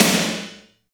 52.07 SNR.wav